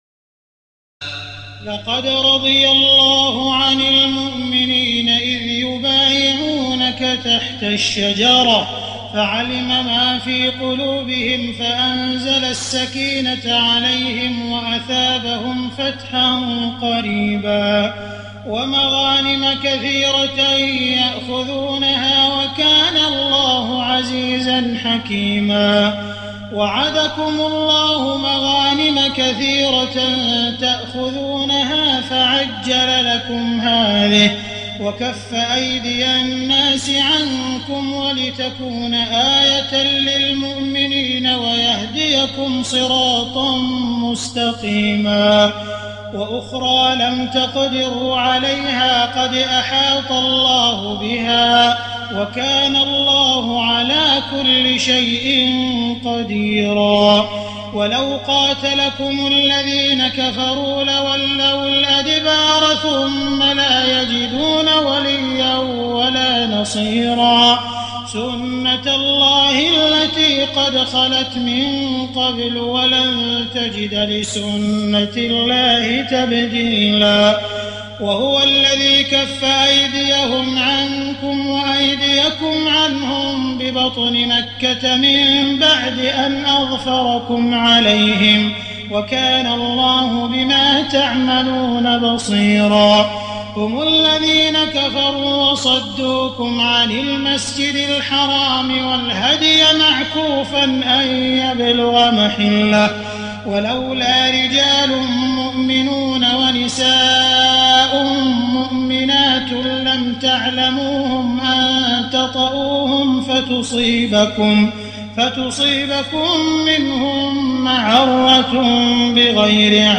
تراويح ليلة 25 رمضان 1419هـ من سور الفتح (18-29) الحجرات و ق و الذاريات (1-37) Taraweeh 25 st night Ramadan 1419H from Surah Al-Fath and Al-Hujuraat and Qaaf and Adh-Dhaariyat > تراويح الحرم المكي عام 1419 🕋 > التراويح - تلاوات الحرمين